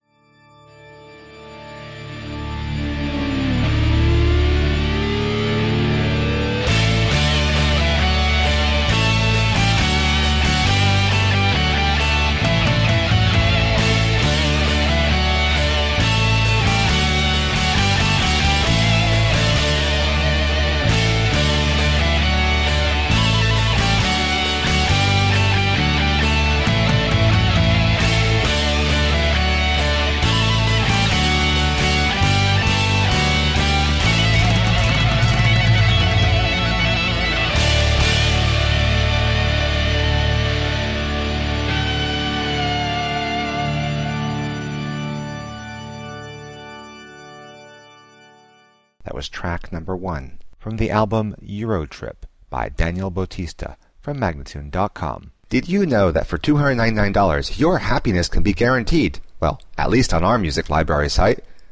Progressive Instrumental, guitar-led metal at its finest!